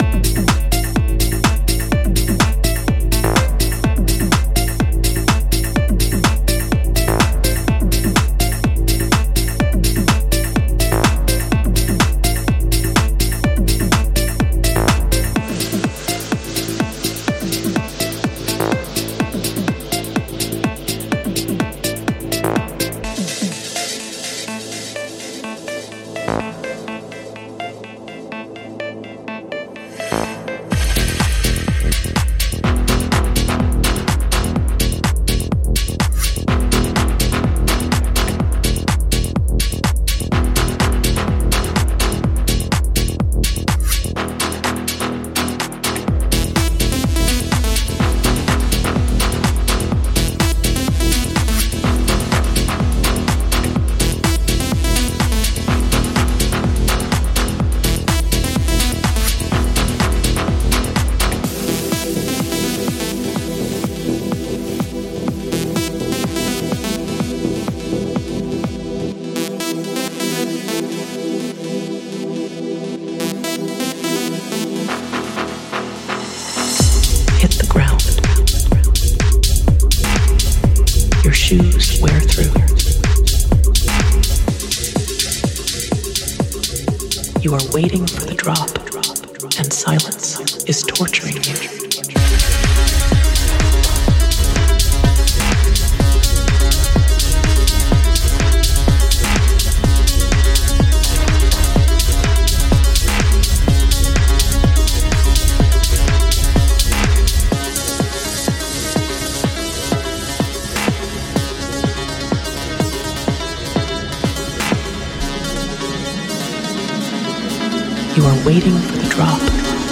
Melodic Techno